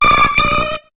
Cri d'Héliatronc dans Pokémon HOME.